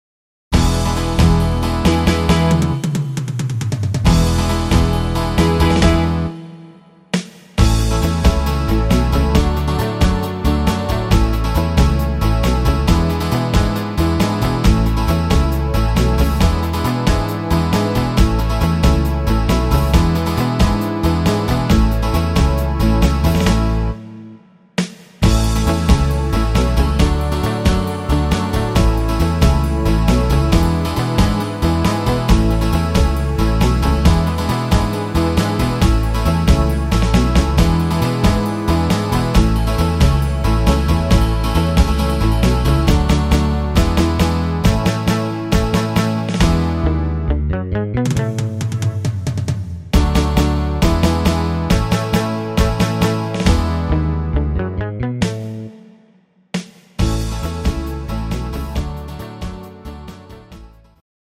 instr. Gitarre